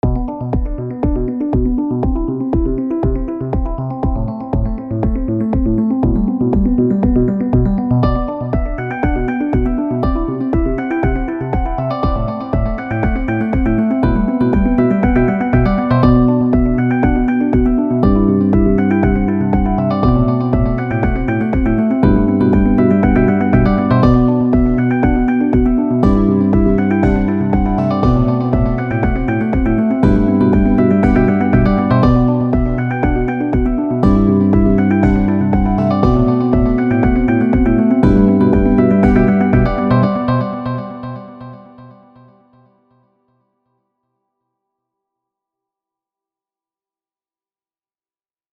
I got FL Studio, experimented with some stuff, and made this: https
Very spacey, the background melody rising and receding is really nice, main melody lacks a clear direction (not bad, just an observation).